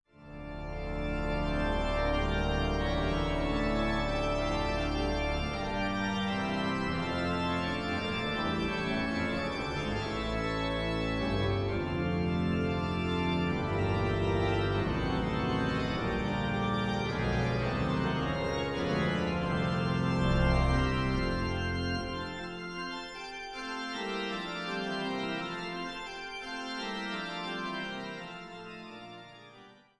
Reichenbach/St. Peter und Paul